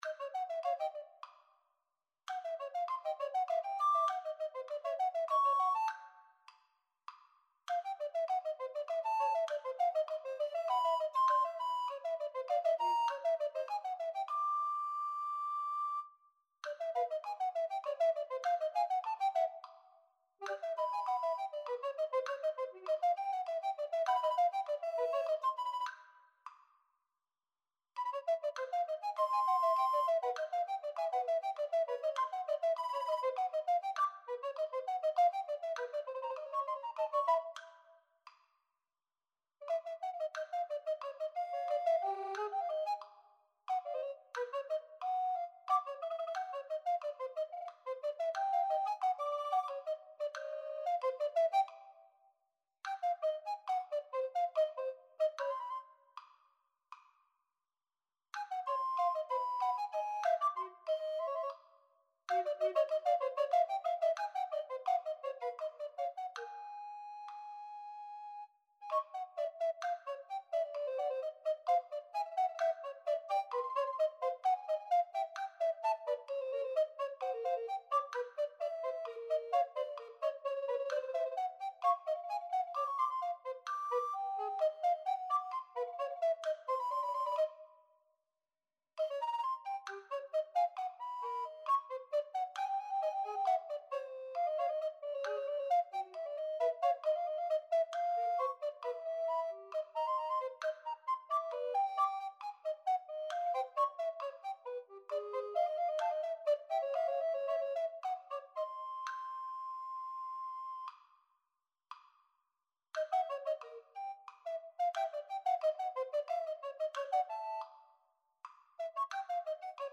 Duo for Alto Recorders
Audio only with click
TwoChooks23Click.mp3